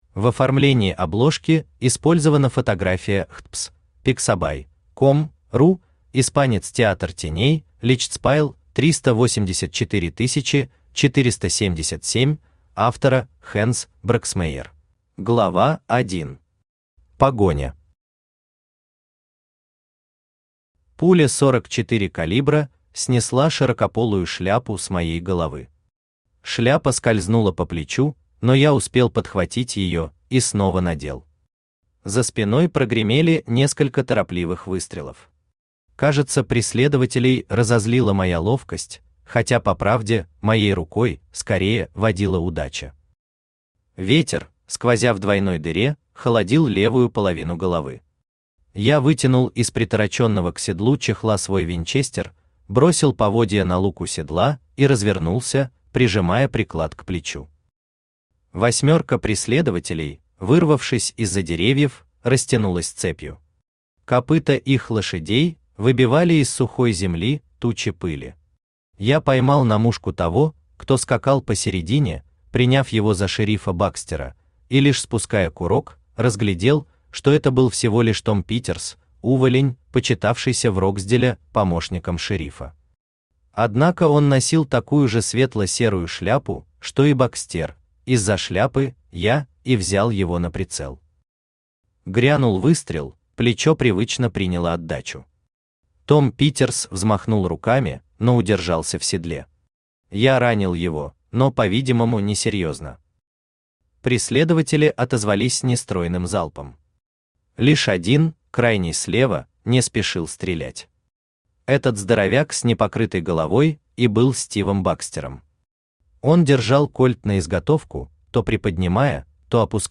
Aудиокнига Призраки Туманной Лощины Автор Дуглас Кейн Читает аудиокнигу Авточтец ЛитРес.